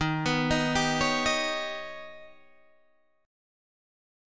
D#+7 chord